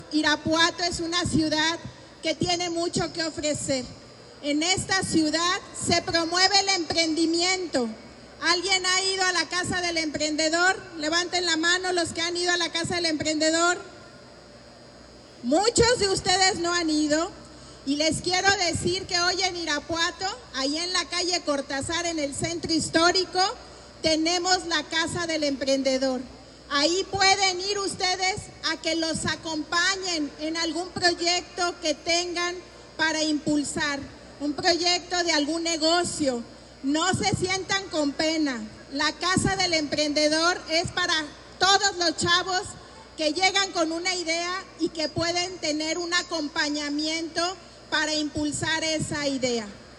AudioBoletines
• Se lleva a cabo la entrega del Premio Municipal de la Juventud 2025
Lorena Alfaro García, presidenta municipal